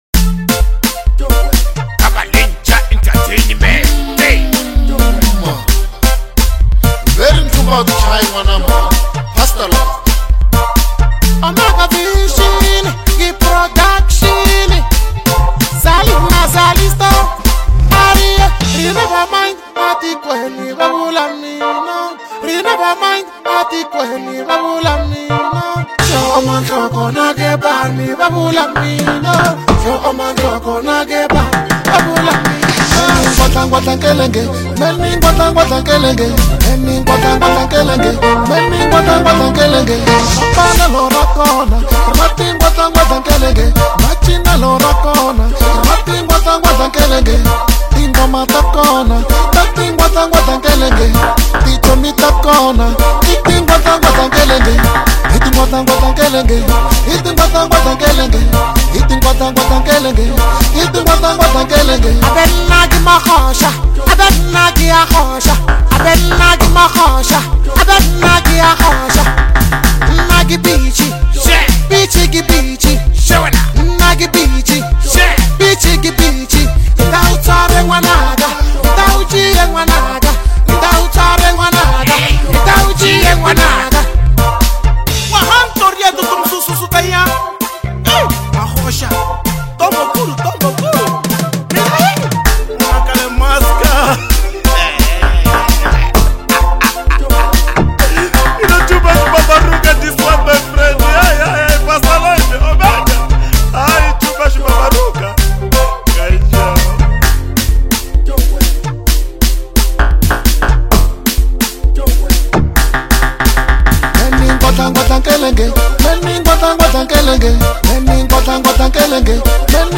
a spiritually enriching anthem
Where to Download More Uplifting Gospel House Music